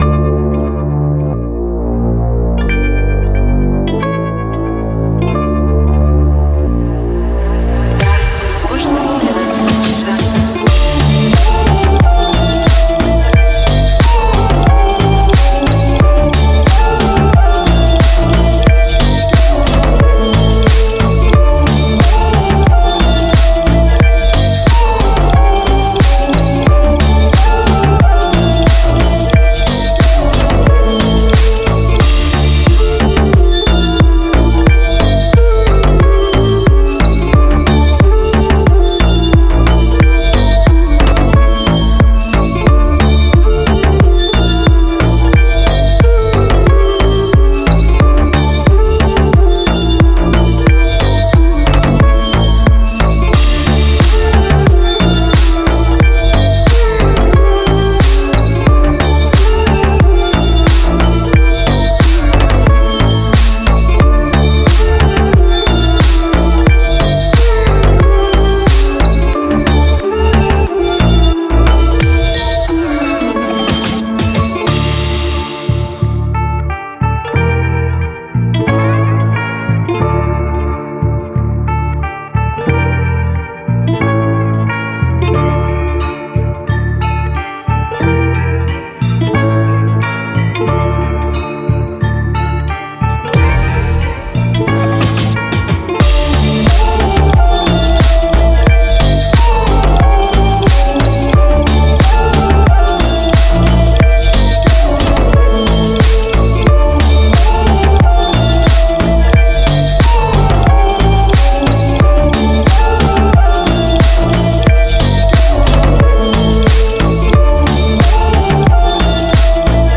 Клубная музыка